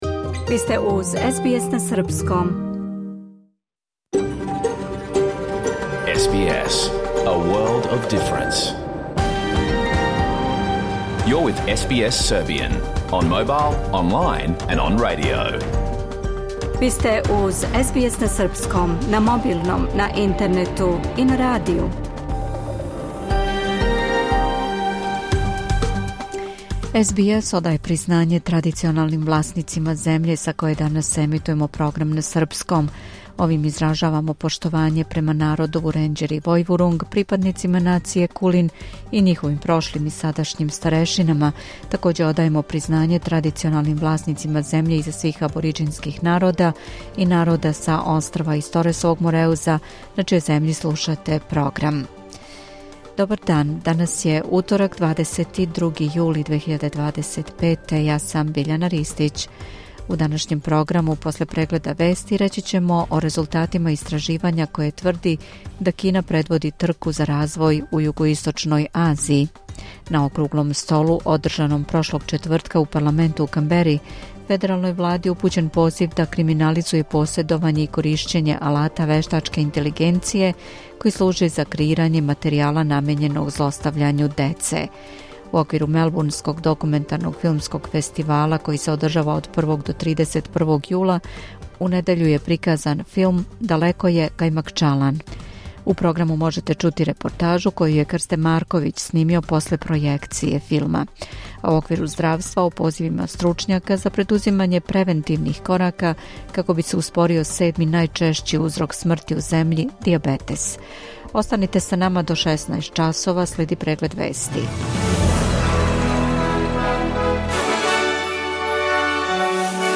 Програм емитован уживо 22. јула 2025. године